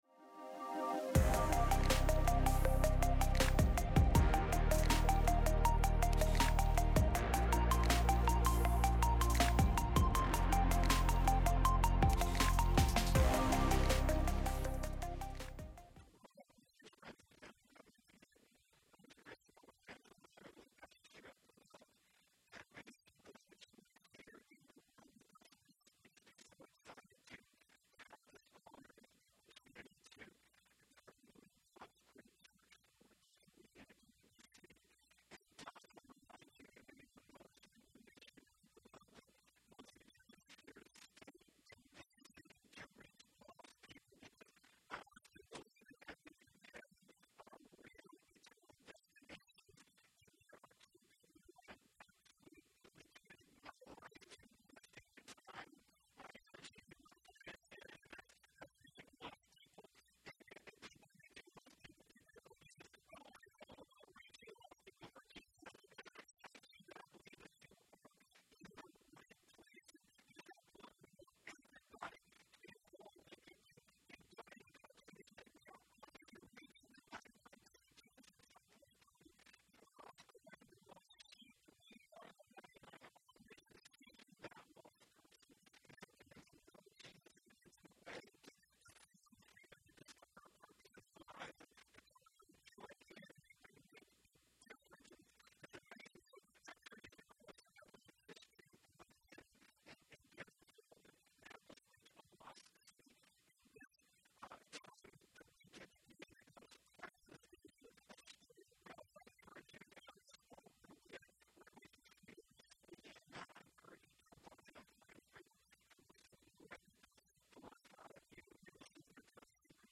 Download Download Relational Monsters Current Sermon How Can I Still Love Hypocritical People?